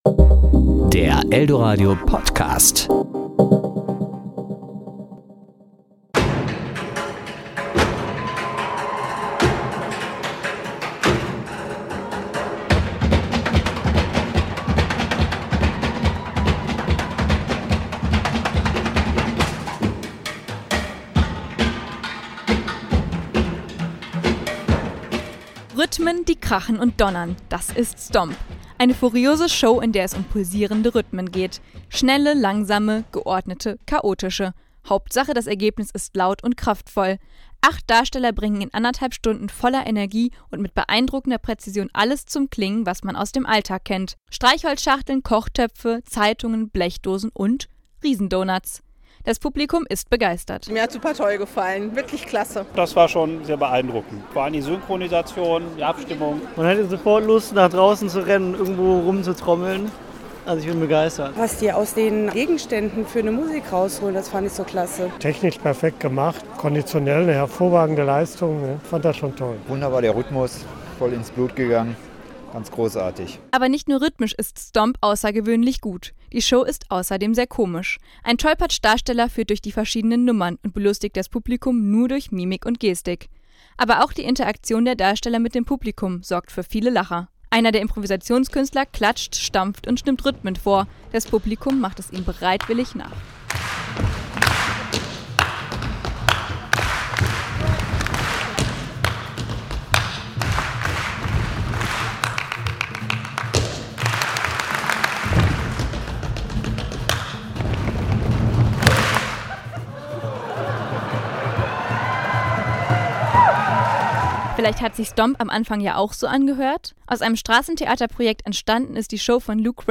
Es wird kräftig gestampft bei der legendären Geräuschveranstaltung "Stomp". Auch im Dortmunder Konzerthaus haben die Ausnahmekünstler Station gemacht.